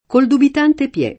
piede [pL$de] s. m. — tronc. piè [pL$+] (non pie’ pié: cfr. piè), oggi usato in alcune locuz. come piè di gallo, piè di porco, piè d’oca, a piè fermo, a piè pari, a piè zoppo, a piè del colle, a piè del monte, a piè di pagina, a piè d’opera, a ogni piè sospinto, ma frequente nell’uso poet. in qualsiasi contesto: Col dubitante piè [